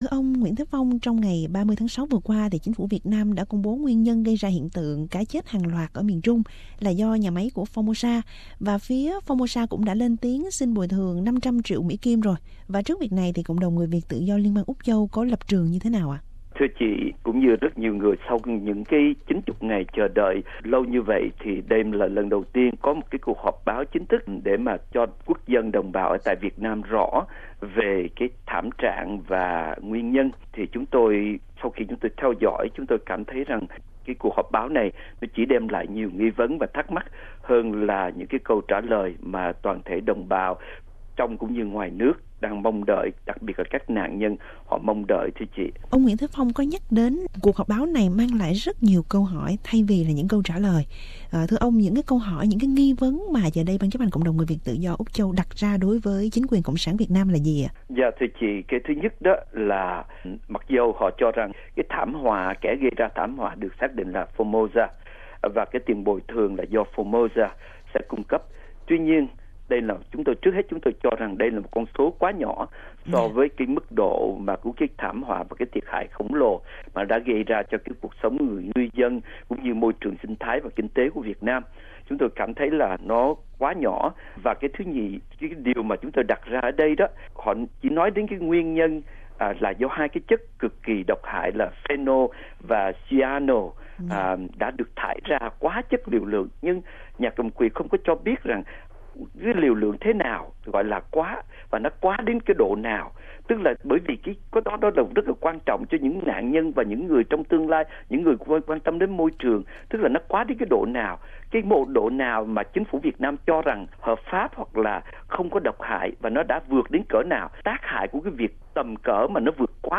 trả lời phỏng vấn của SBS.